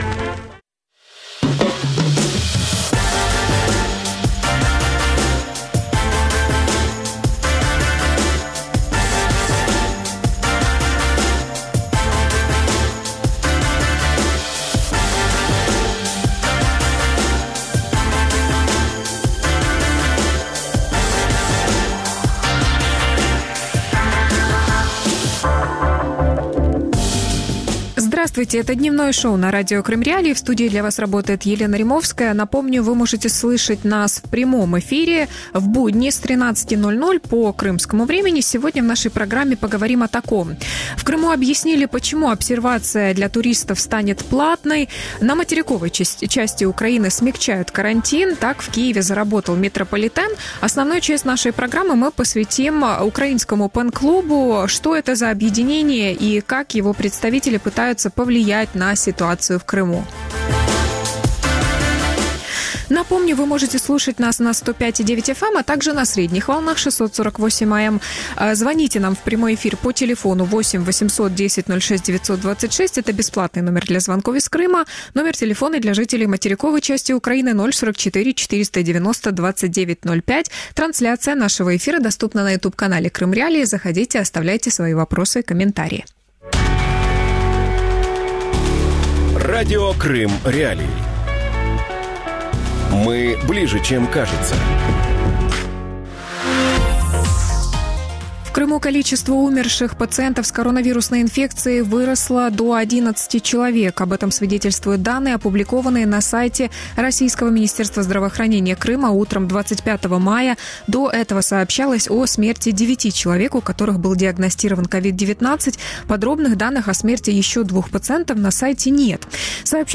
ПЕН-клуб и Крым | Дневное ток-шоу